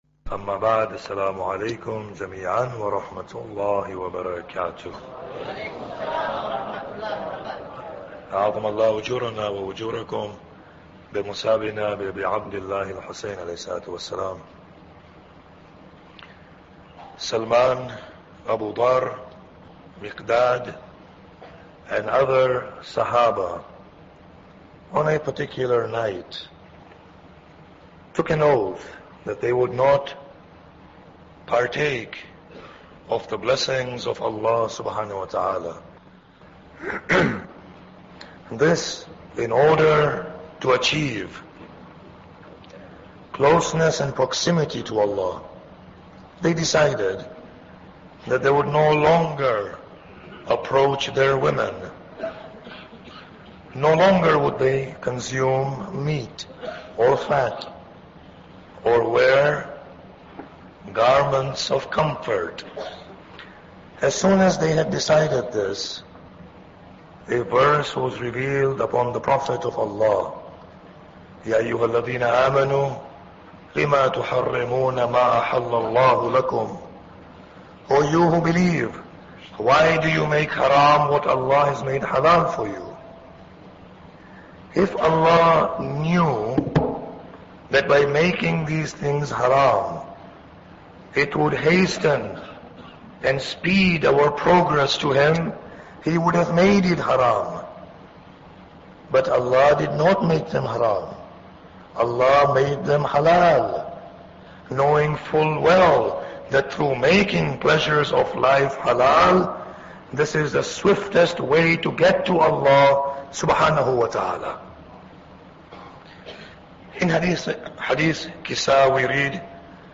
Muharram Lecture 9